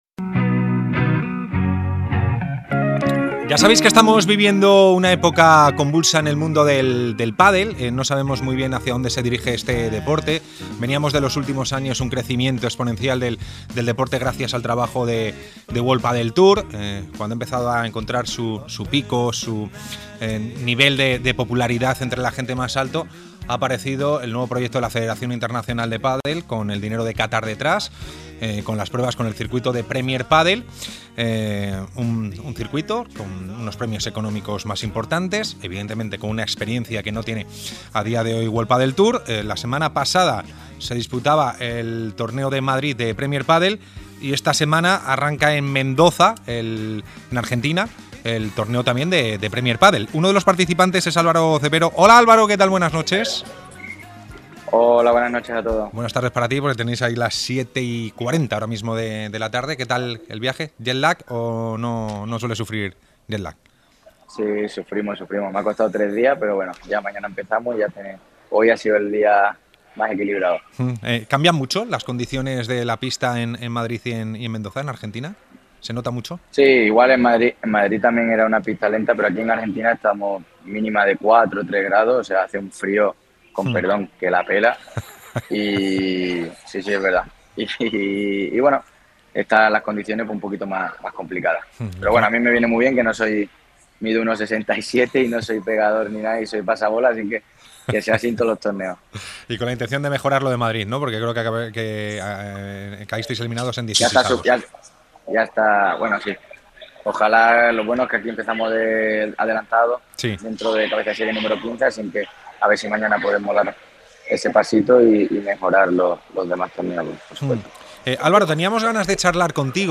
El jugador de pádel pasa por El Larguero para hablar sobre la asociación de jugadores de pádel, presidida por Ale Galán, cómo se formó y las decisiones tomadas hasta el momento.